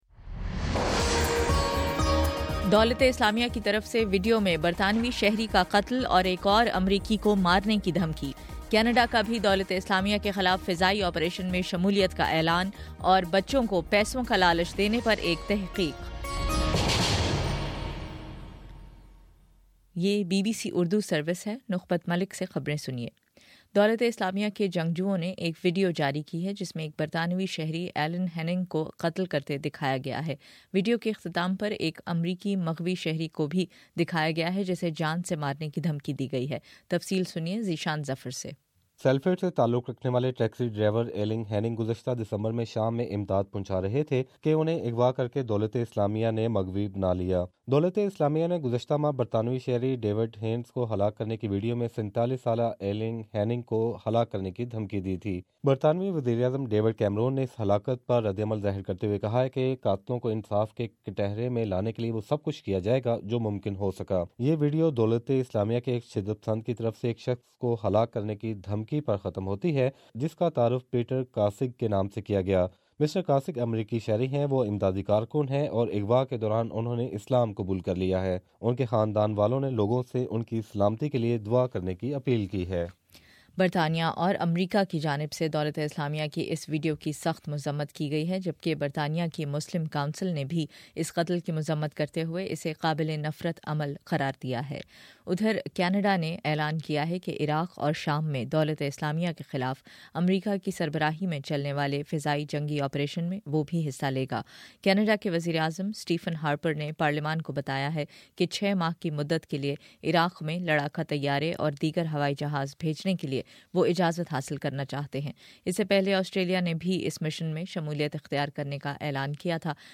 اکتوبر 04: صبح نو بجے کا نیوز بُلیٹن